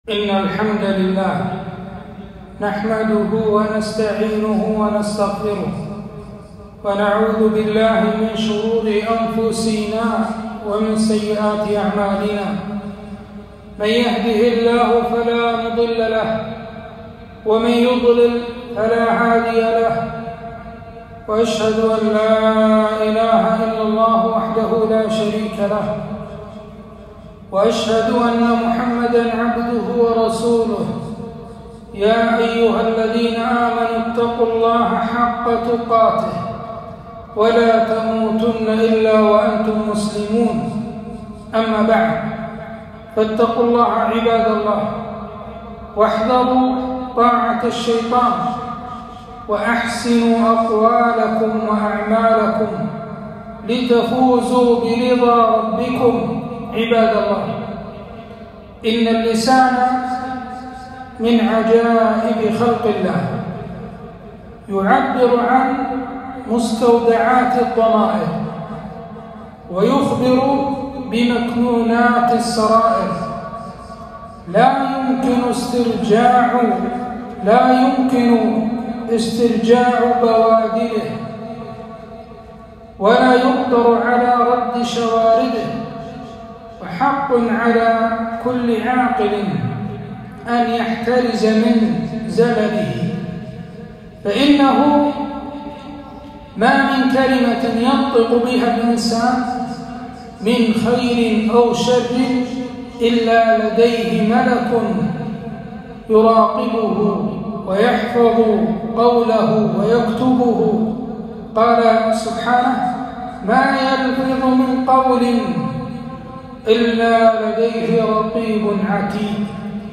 خطبة - آفات اللسان